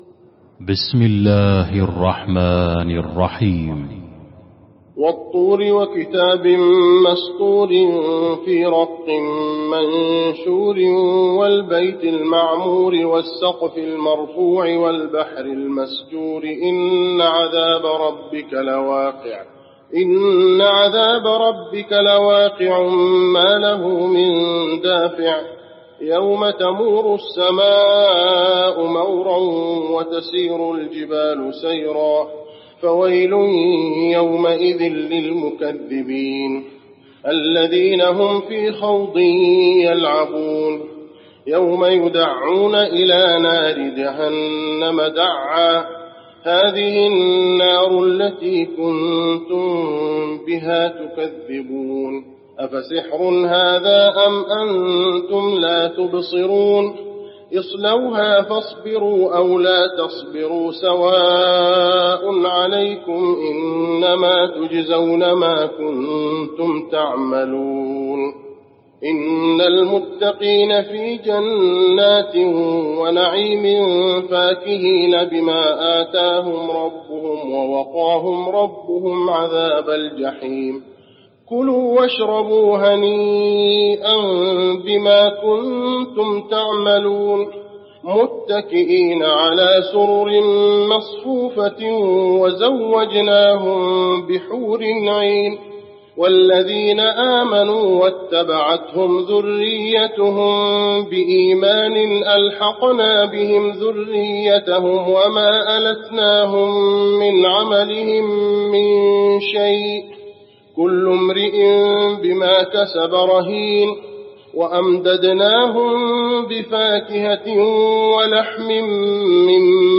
المكان: المسجد النبوي الطور